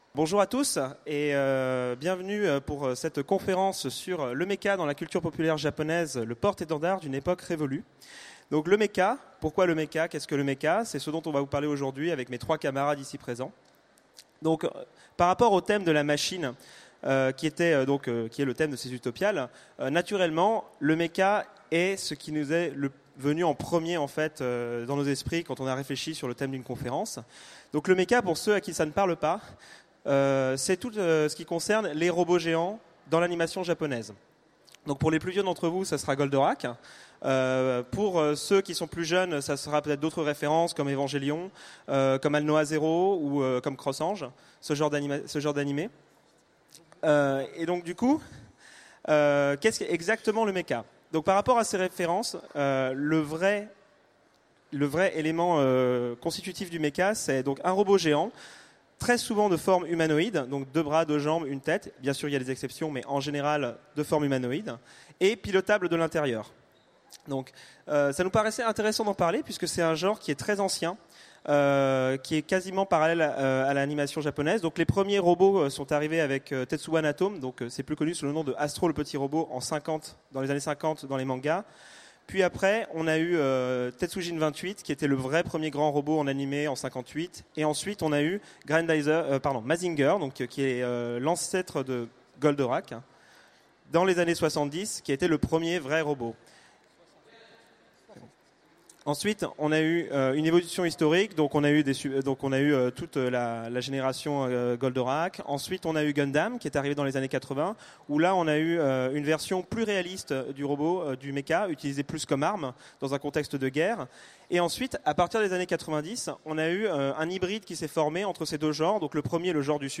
Utopiales 2016 : Conférence Le mecha dans la culture populaire japonaise